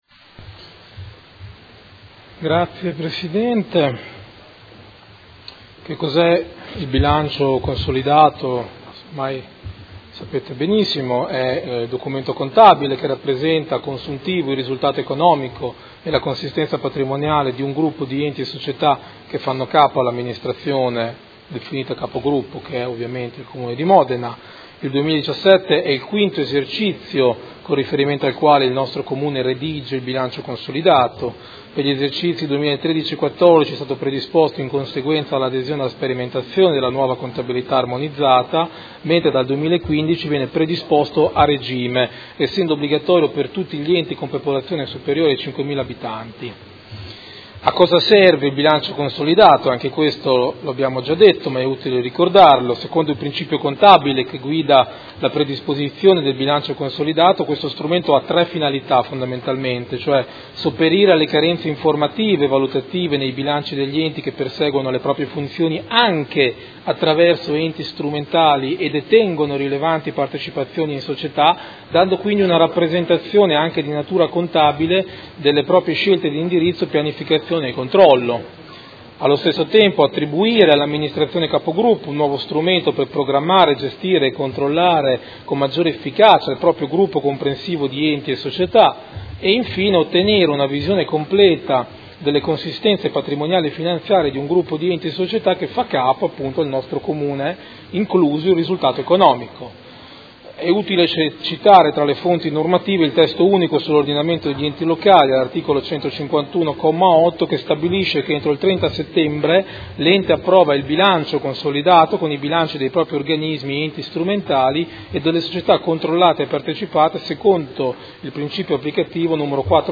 Seduta del 27/09/2018. Proposta di deliberazione: Bilancio Consolidato 2017 del Gruppo Comune di Modena - Verifica finale del controllo sulle società partecipate per l’esercizio 2017 e monitoraggio infrannuale 2018